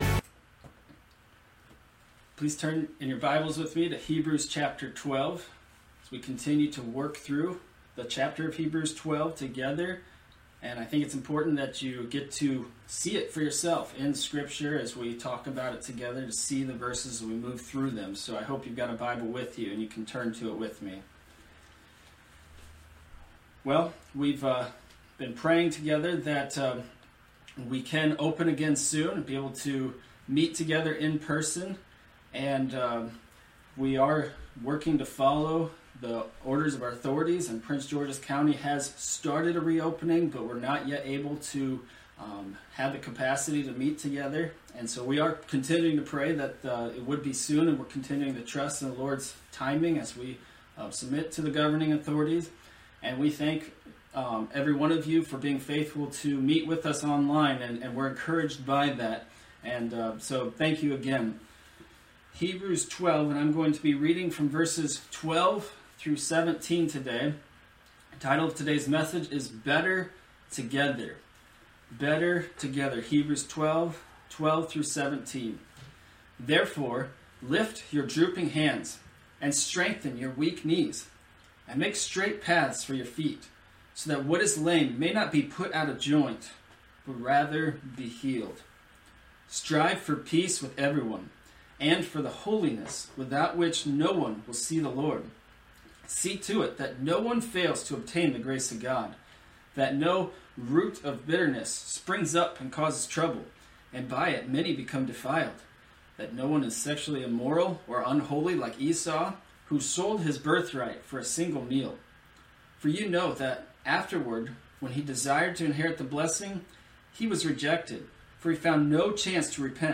Online Sermons